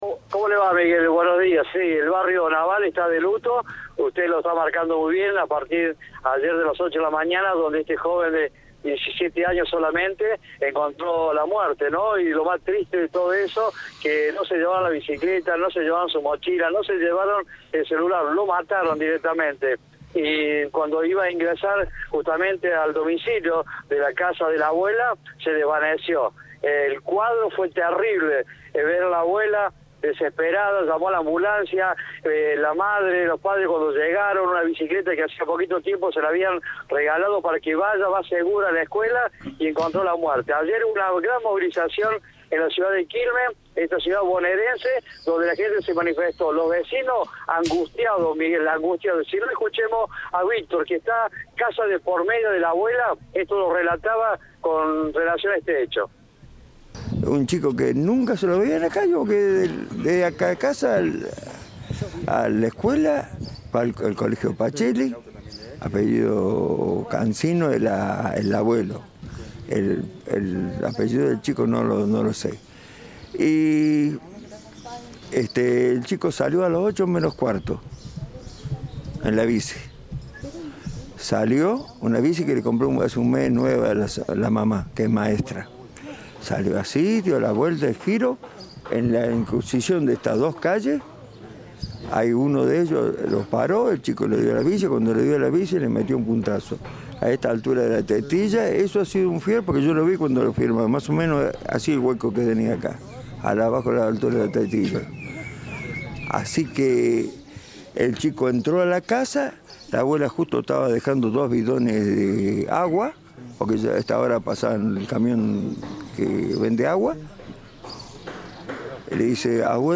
Informe
Entrevista